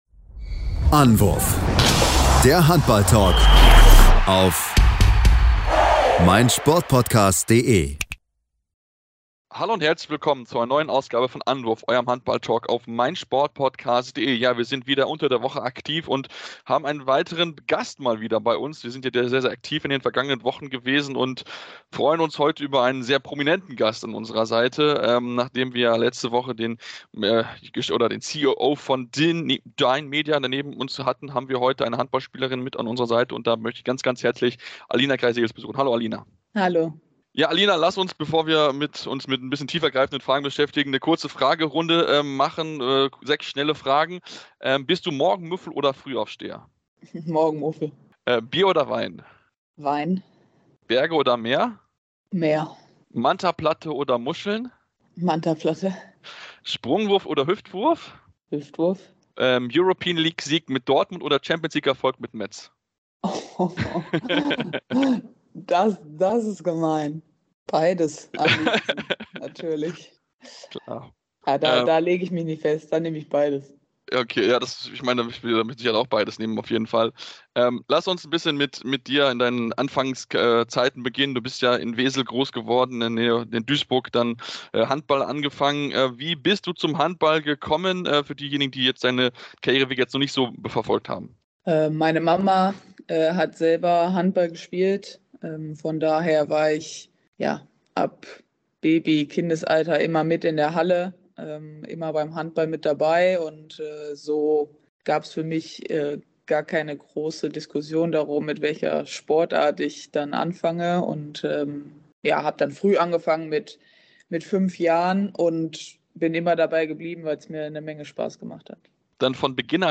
Beschreibung vor 3 Jahren Es ist mal wieder Interview-Zeit bei Anwurf.